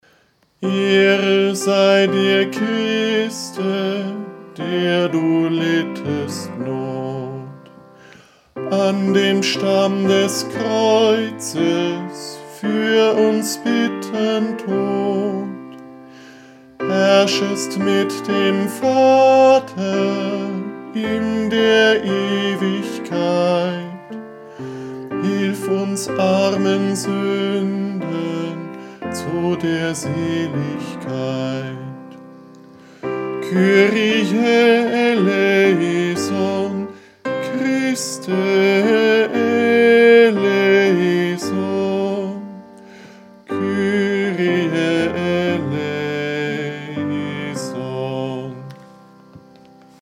Eingesungen: Liedvortrag